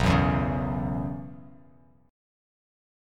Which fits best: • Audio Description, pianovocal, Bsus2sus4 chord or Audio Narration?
Bsus2sus4 chord